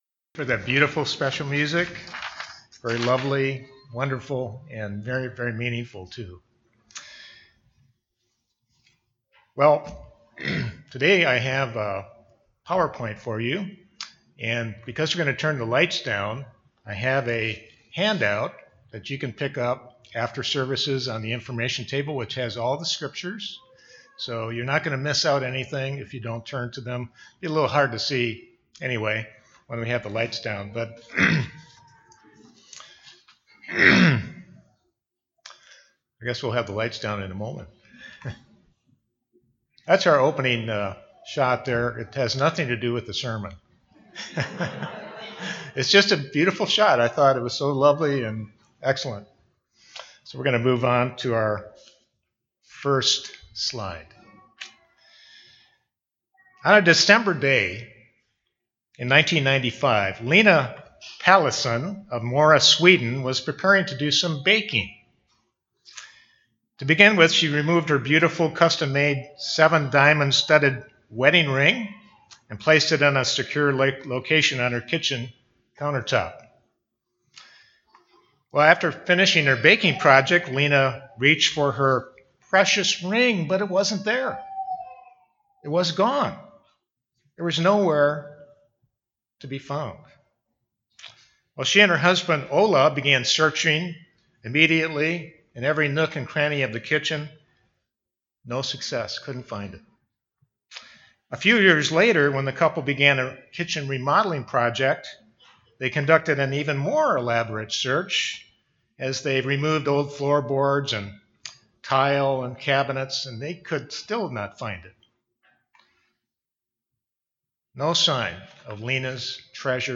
This sermon was given at the Drumheller, Alberta 2018 Feast site.